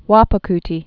(wäpə-ktē)